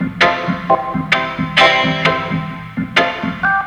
ORGANGRAT1-L.wav